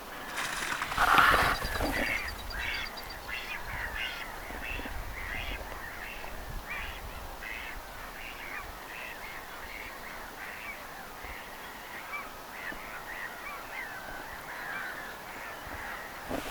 sinisorsakoiraan ääniä,
kun se nousee läheltä lentoon
Vähän erilaista on koiraan ääntely
sinisorsakoiras_nousee_lentoon_lahelta_sinisorsakoiraan_aantelya_erilaista_kuin_naaraalla.mp3